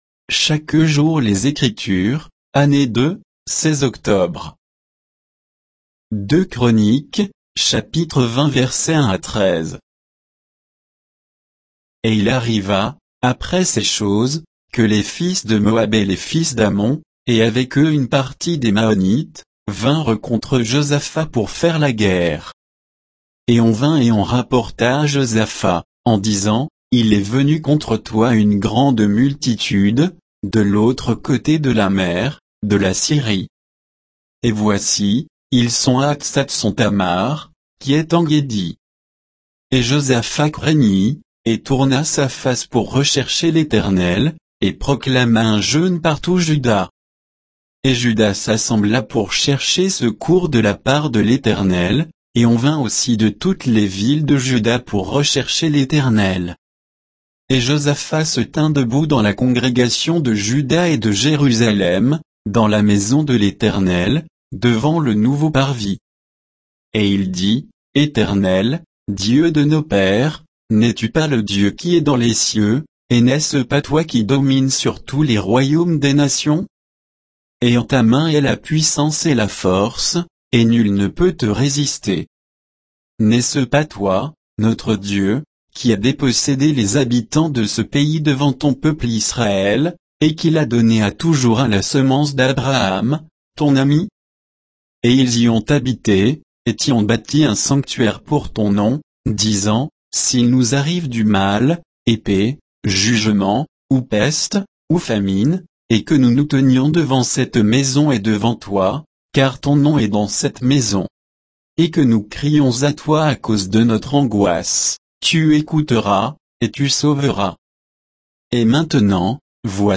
Méditation quoditienne de Chaque jour les Écritures sur 2 Chroniques 20, 1 à 13